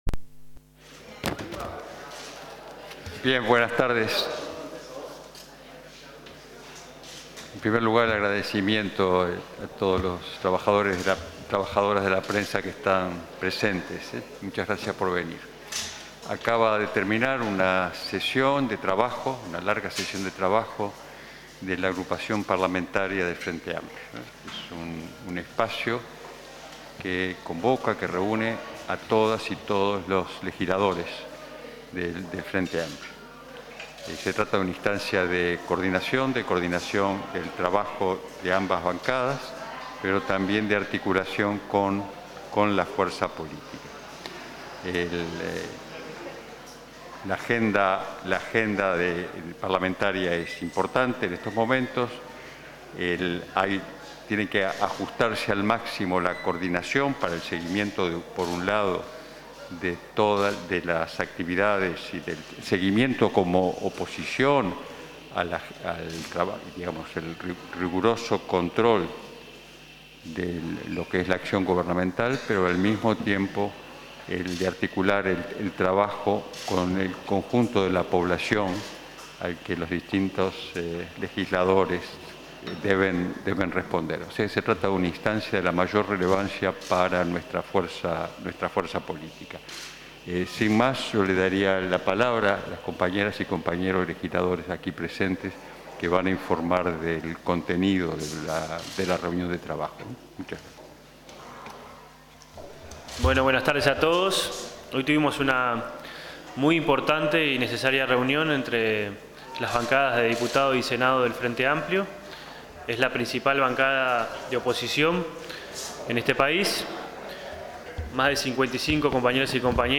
Una vez culminada la jornada, se realizó conferencia en La Huella de Seregni, donde el Coordinador Ehrlich destacó la importancia de la reunión.